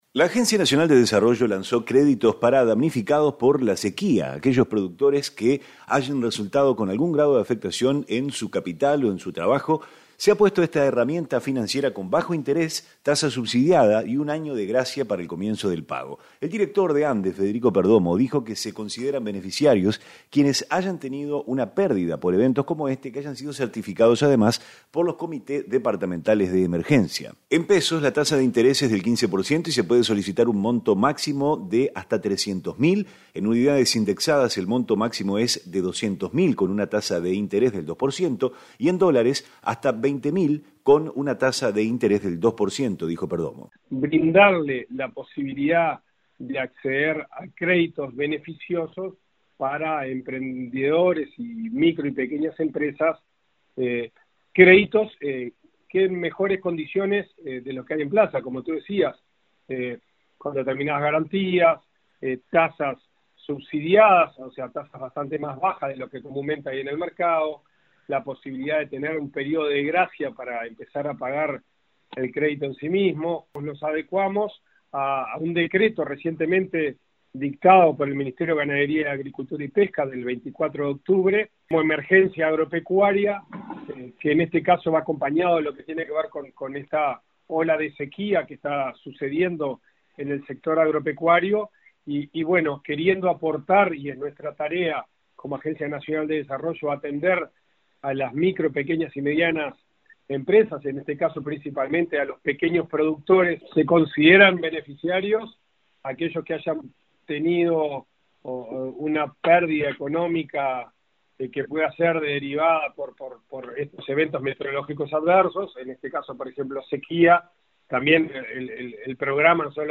REPORTE-CREDITOS-POR-SEQUIA-1.mp3